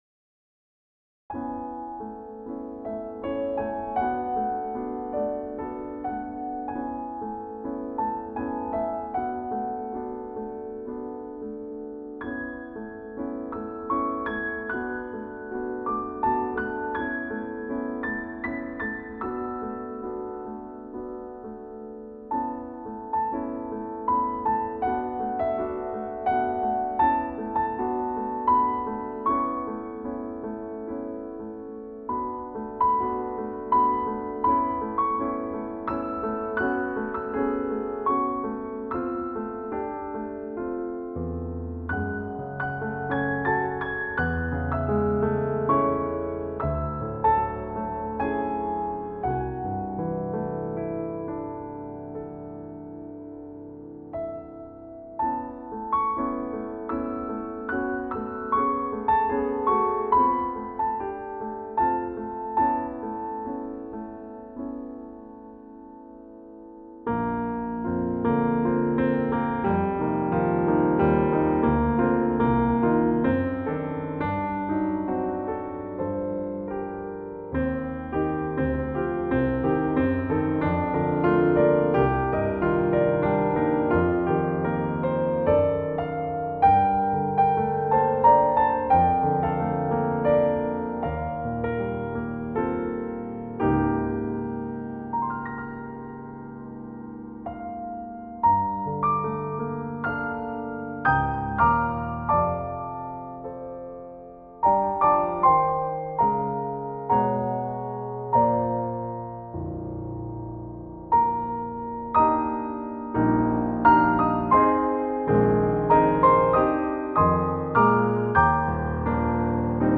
This harmonically rich piano solo setting
includes three stanzas, each modulating to a new key.
Keys: A Major, B-flat Major, E-flat Major